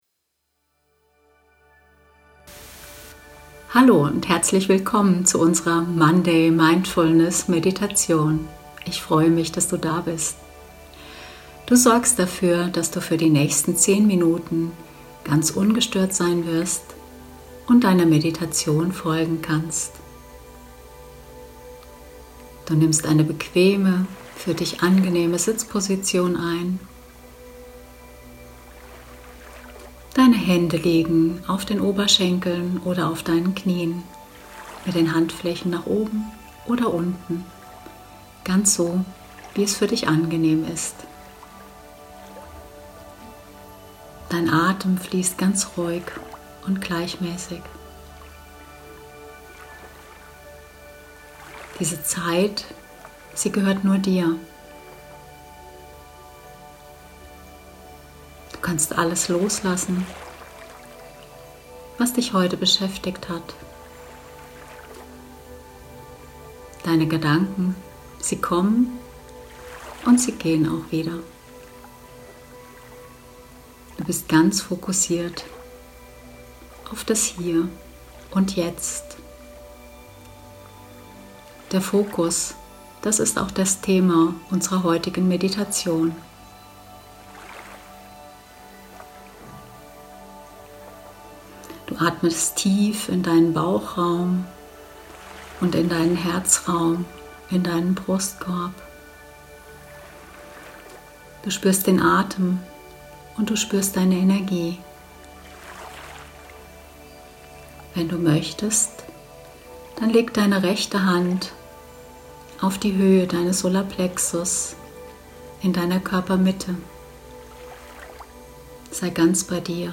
Hörprobe: Meditation Fokus
rossisyoga-meditation-fokus.mp3